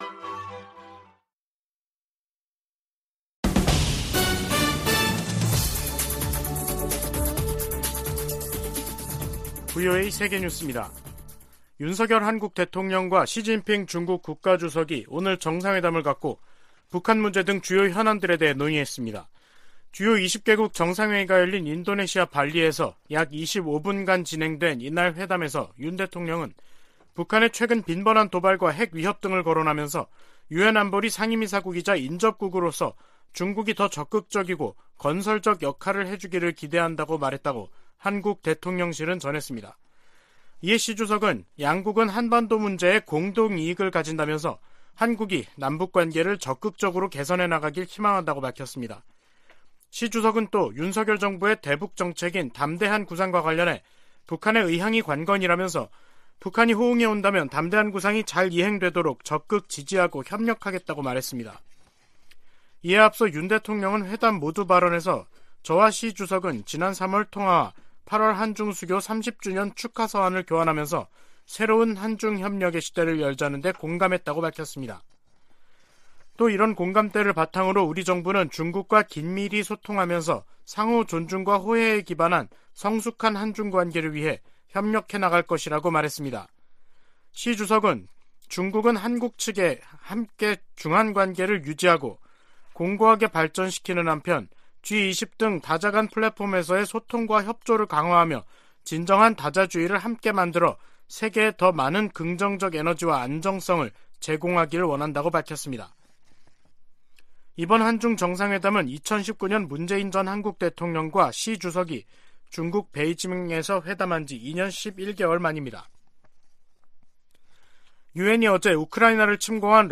VOA 한국어 간판 뉴스 프로그램 '뉴스 투데이', 2022년 11월 15일 3부 방송입니다. 조 바이든 미국 대통령은 인도네시아에서 열린 미중 정상회담에서 시진핑 국가주석에게 북한이 핵실험 등에 나서면 추가 방위 조치를 취할 것이라고 말했다고 밝혔습니다. 미국과 중국의 정상회담에서 북한 문제 해법에 대한 견해차가 확인되면서 한반도를 둘러싸고 높아진 긴장이 지속될 전망입니다.